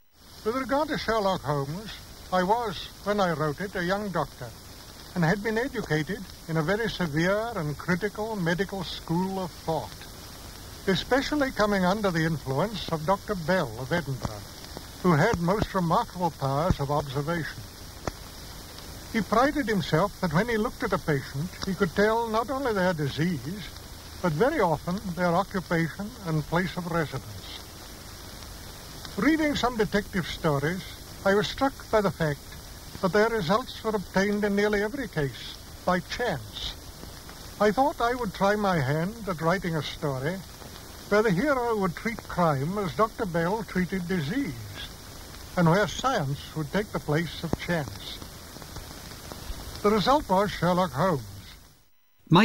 speaking in 1930.
Conan Doyle 1930.mp3